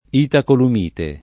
itacolumite [ itakolum & te ]